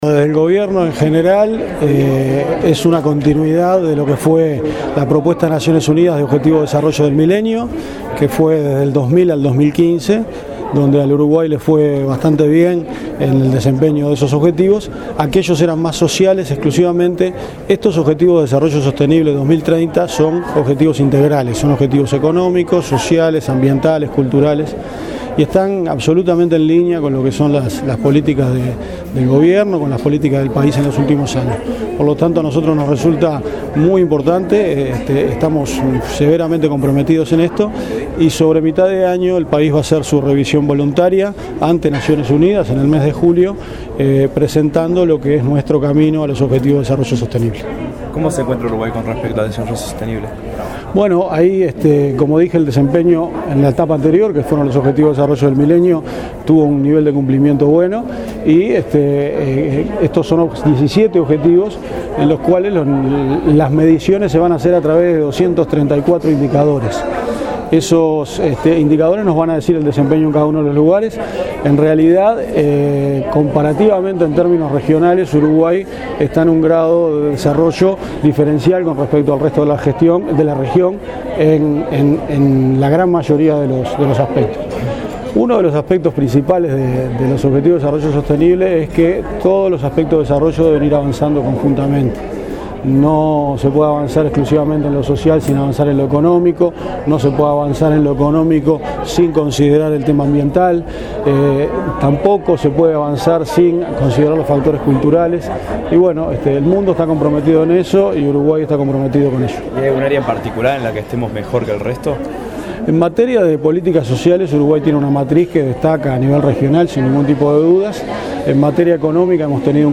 Uruguay está en un grado de desarrollo diferencial con respecto al resto de la región en la gran mayoría de los aspectos, como en políticas sociales y económicas. Así lo afirmó el director de OPP, Álvaro García, durante el evento "Oportunidades para el país: Uruguay en la senda de los Objetivos de Desarrollo Sostenible", que se realizó en la sede de Antel.